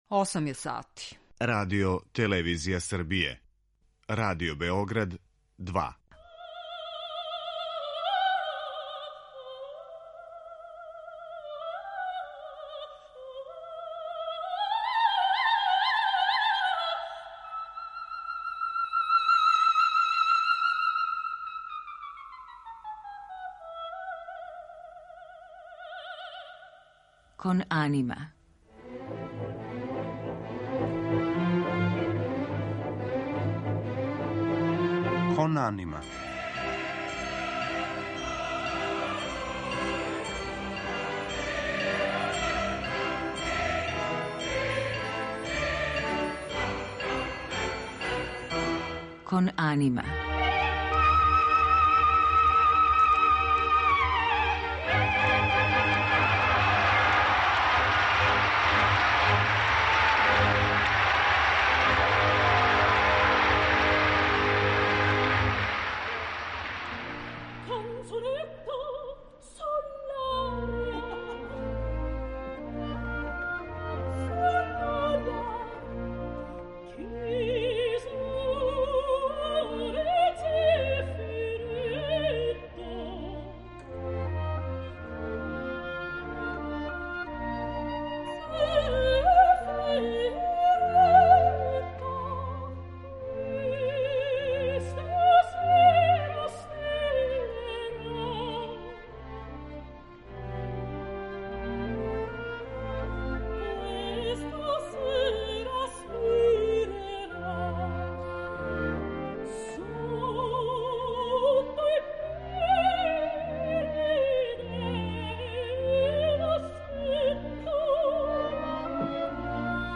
Данас ћемо је слушати у улози грофице Алмавива из Моцартове „Фигарове женидбе", Веберове Агате и Гершвинове Кларе, а отпеваће и неколико соло песама Франца Шуберта.